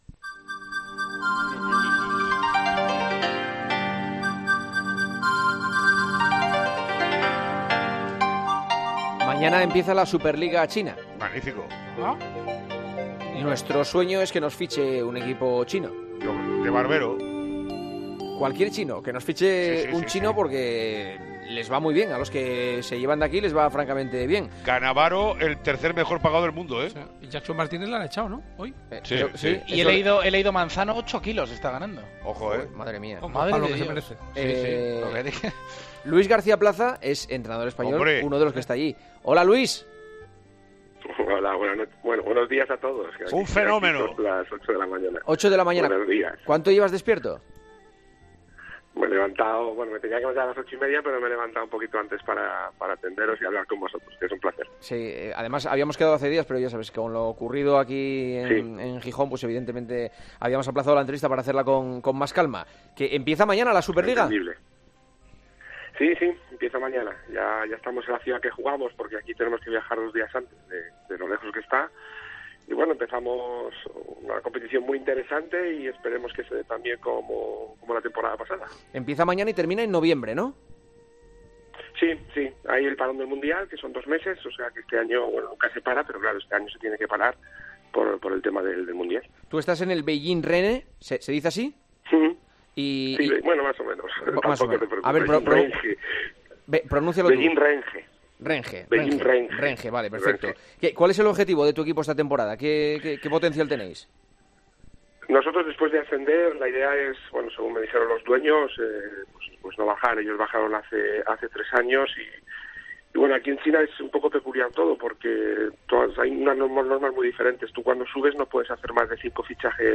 El entrenador español atendió la llamada de El Partidazo de COPE en vísperas de su debut en la Primera División de la Liga China, al frente del...